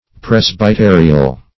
Search Result for " presbyterial" : The Collaborative International Dictionary of English v.0.48: Presbyterial \Pres`by*te"ri*al\, a. [Cf. F. presbyt['e]ral.]